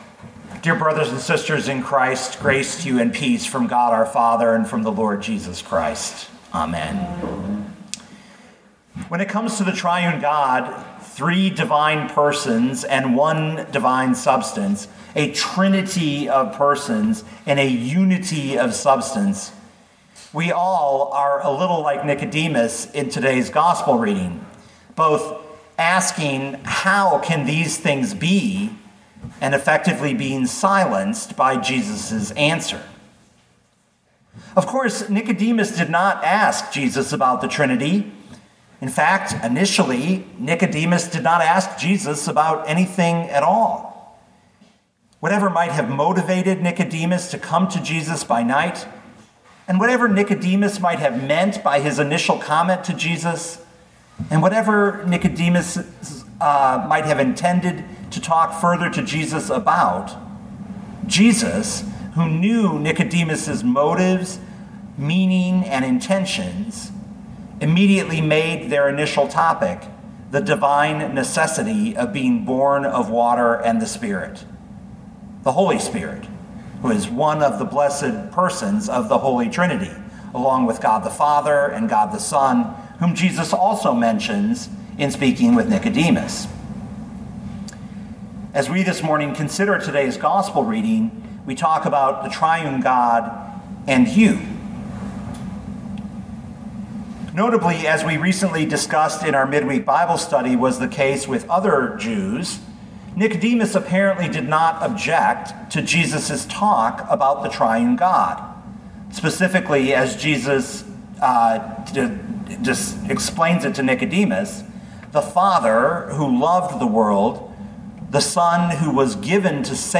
Sermons
The Holy Trinity, May 30, 2021